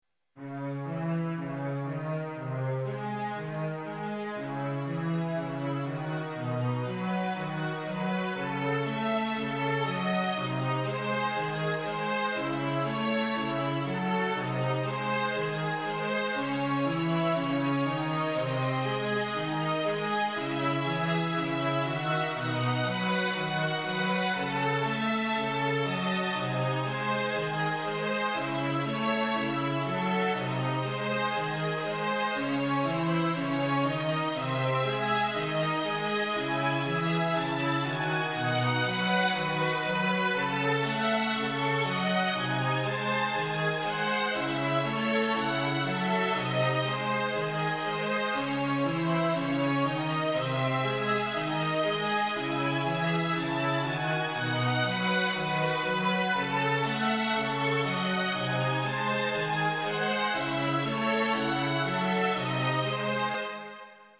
Takové útržky z klasiky.